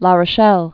(lä rə-shĕl, rô-)